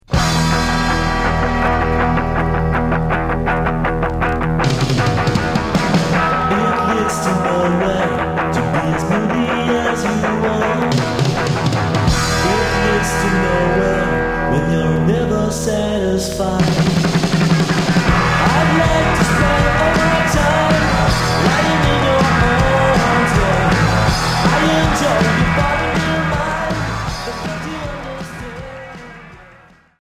Rock Premier 45t